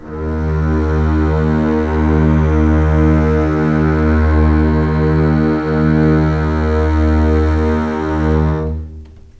D#2 LEG MF L.wav